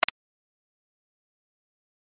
決定ボタン05 - 音アリー